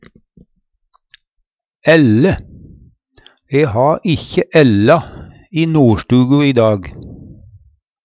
ælle - Numedalsmål (en-US)